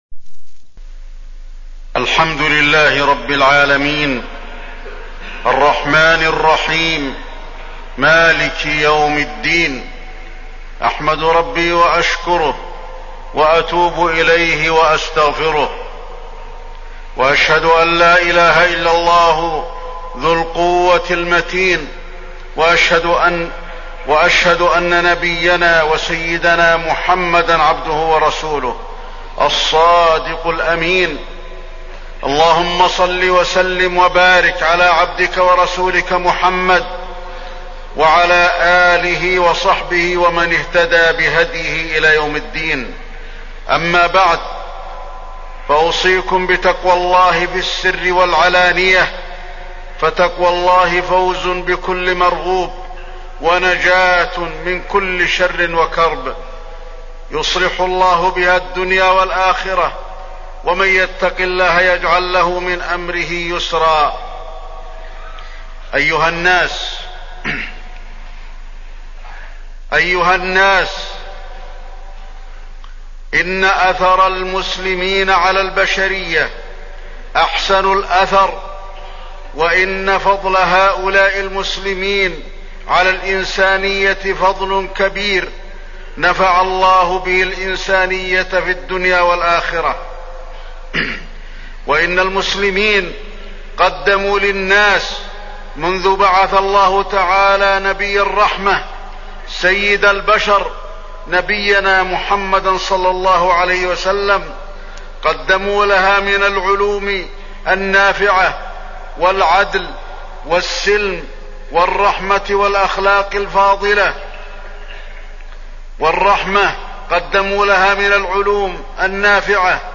تاريخ النشر ١٢ محرم ١٤٣٠ هـ المكان: المسجد النبوي الشيخ: فضيلة الشيخ د. علي بن عبدالرحمن الحذيفي فضيلة الشيخ د. علي بن عبدالرحمن الحذيفي نصرة غزة